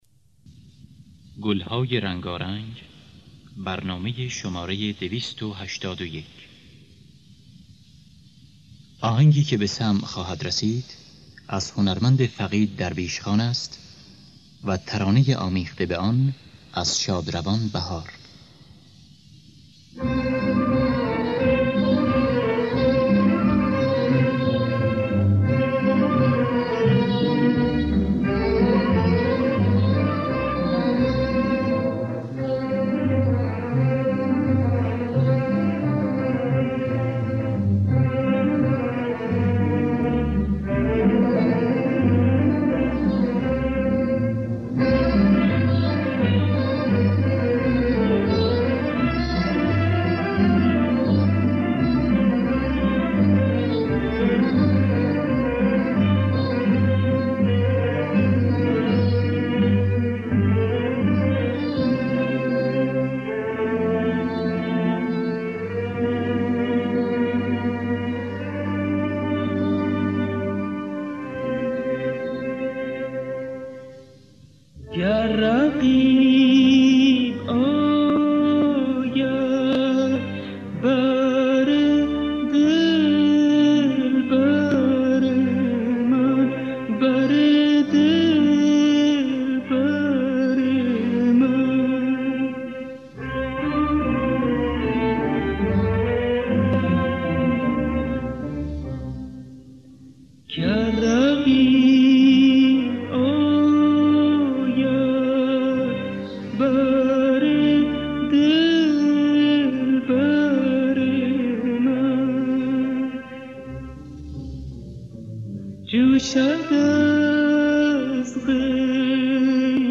در دستگاه بیات ترک